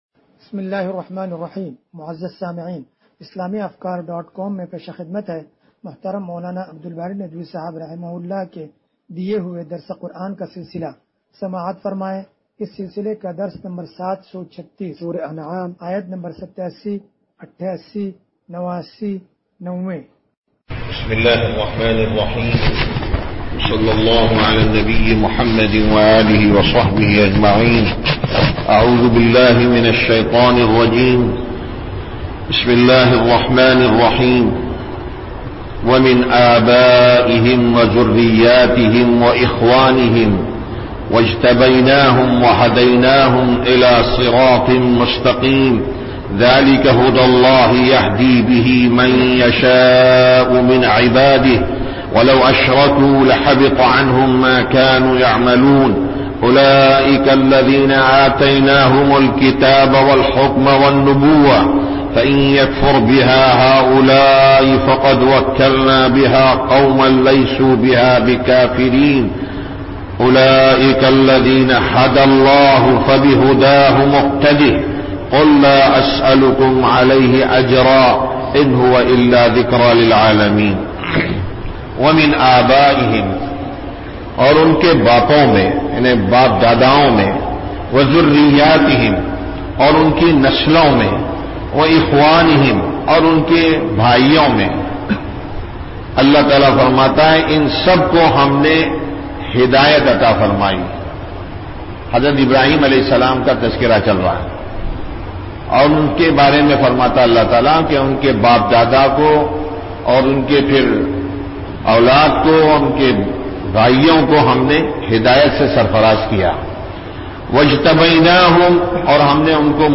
درس قرآن نمبر 0736
درس-قرآن-نمبر-0736.mp3